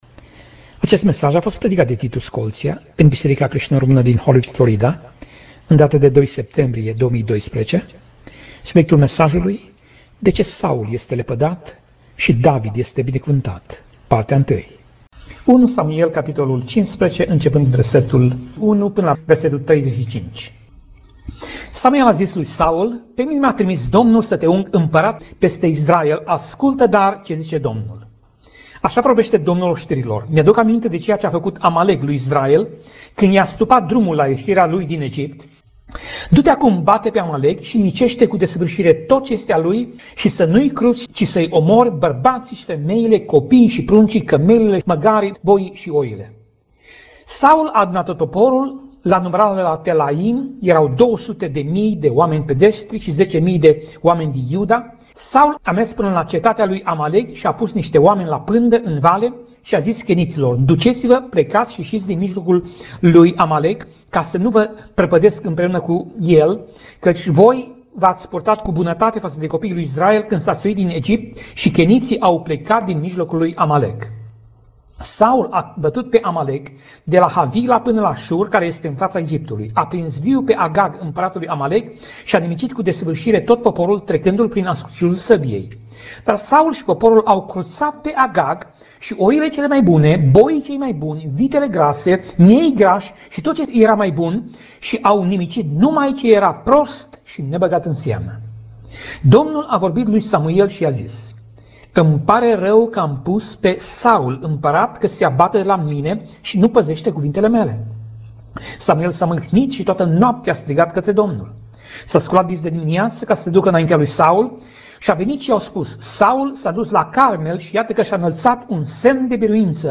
Pasaj Biblie: 1 Samuel 15:1 - 1 Samuel 15:35 Tip Mesaj: Predica